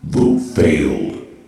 vote_failed.ogg